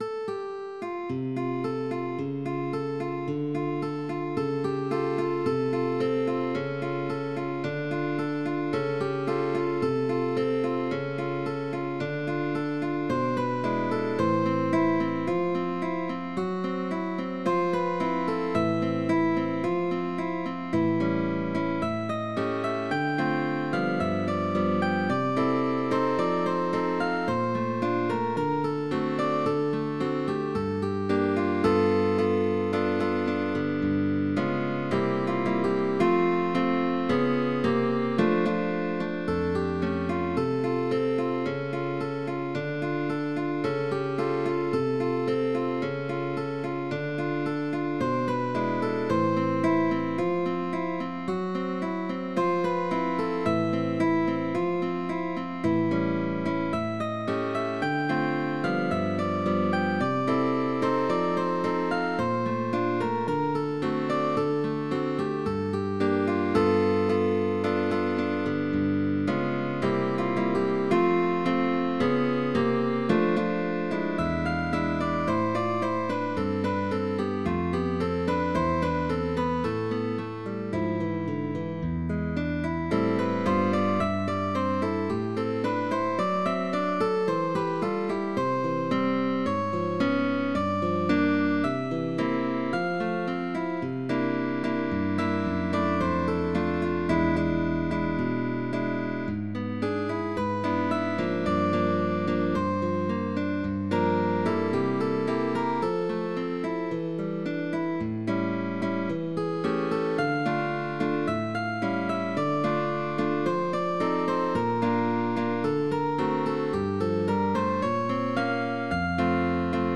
Concert duos